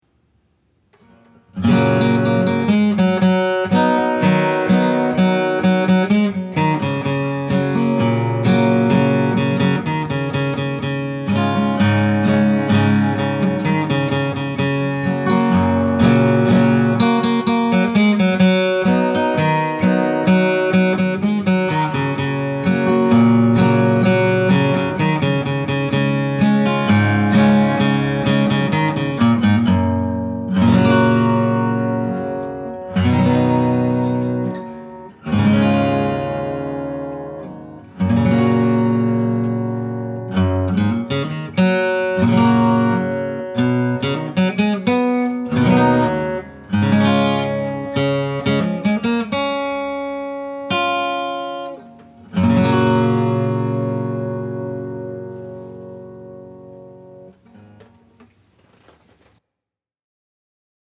It has a balanced tone and is fully “played-in”.